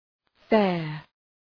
Προφορά
{feər}